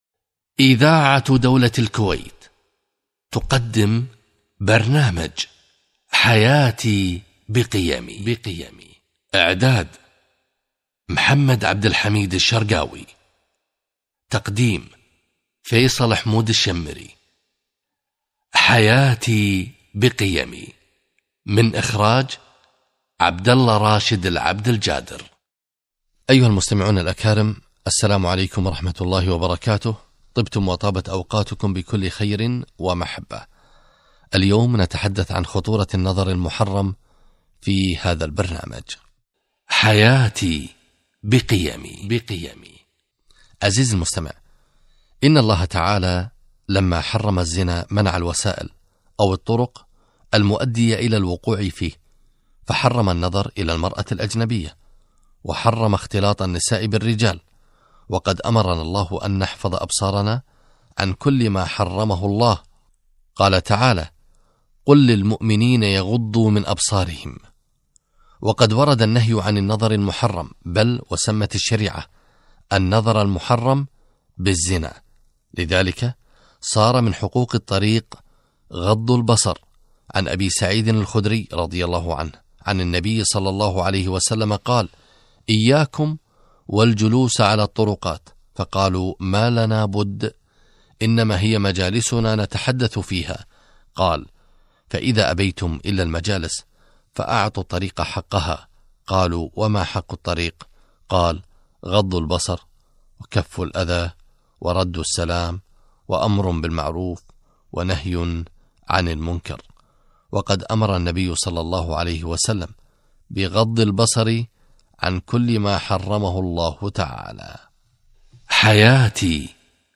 غض البصر - لقاء إذاعي